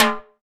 9HI TIMBAL.wav